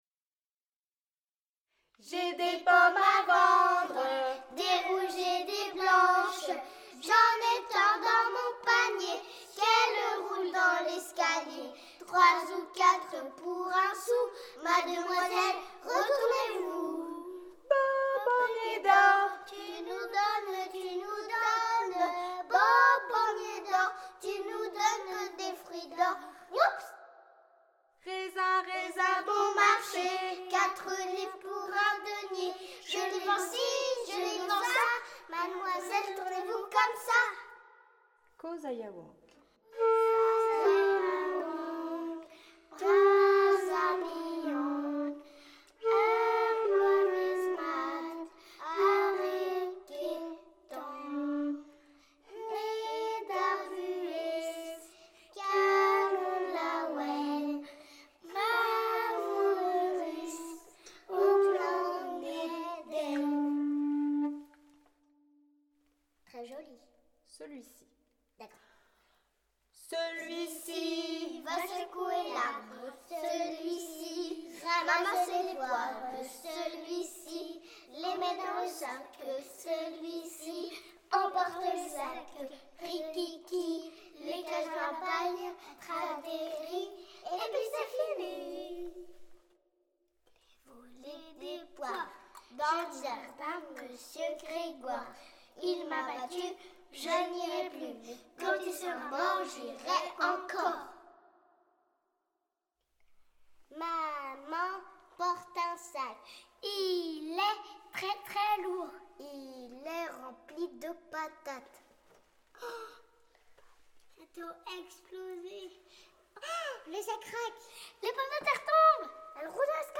Les élèves ont pu expérimenter le travail de studio avec le projet Musik Mekanik.
Les enregistrement de groupes d’élèves ont été effectués à la Carène, en avril 2016.